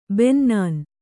♪ bennān